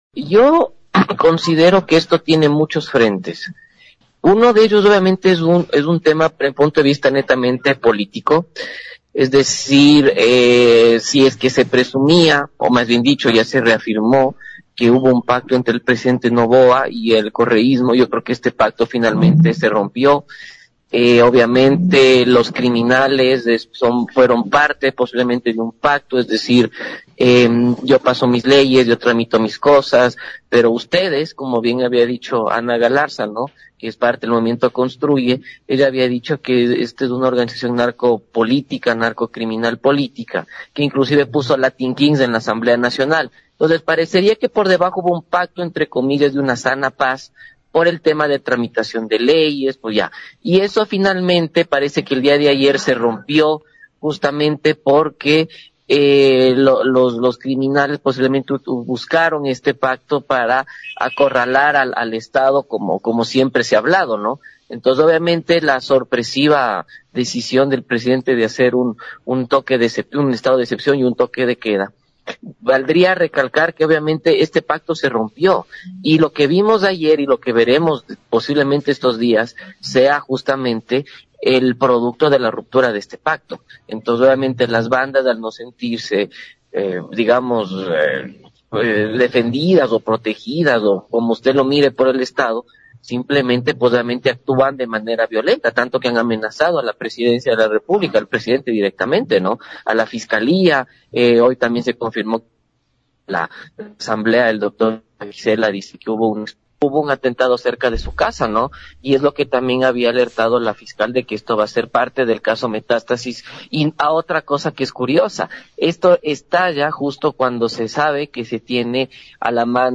el día de hoy en su diálogo con Nina Radio